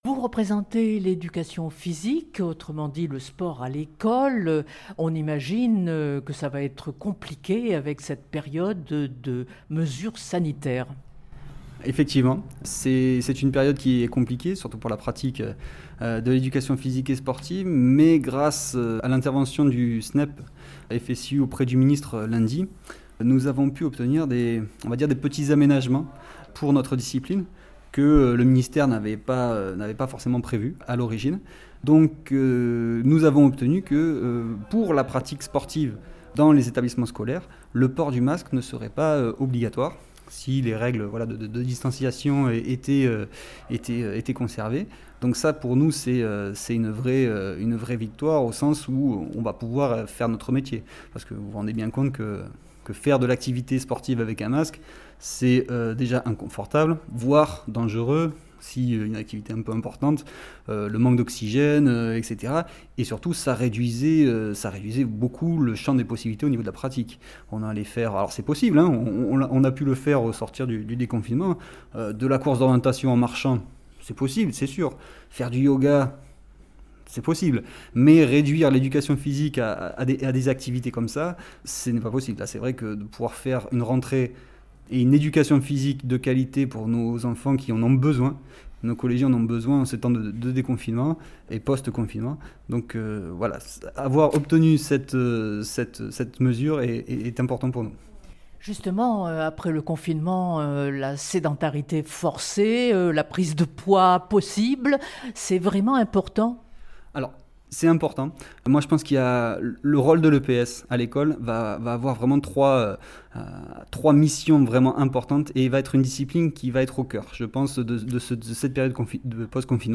La deuxième consiste à apporter du «bien être» aux enfants, et la troisième, retrouver les relations avec d’autres, hors de la famille et des réseaux sociaux. Entretien.